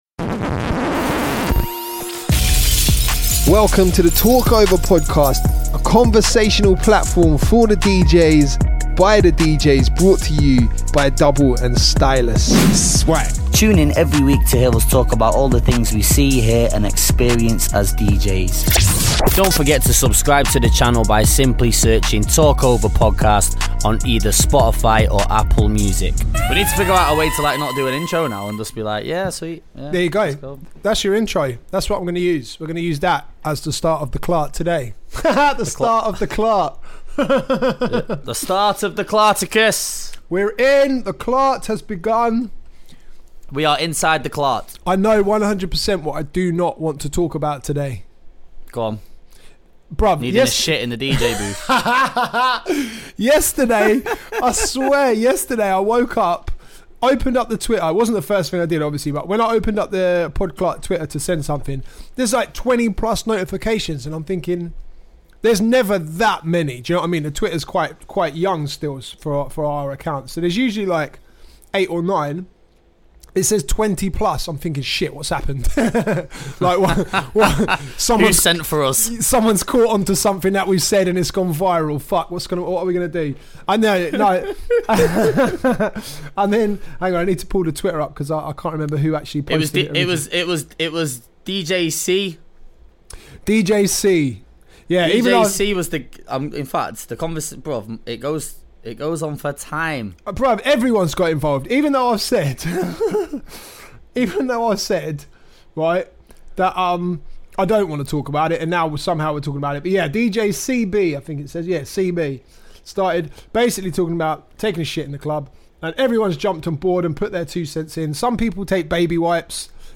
We discuss relevant topics, giving our unfiltered & honest opinions about anything that comes up in conversation!